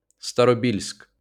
uttal(fil); ryska: Старобельск, Starobelsk) är en stad i Luhansk oblast i östra Ukraina.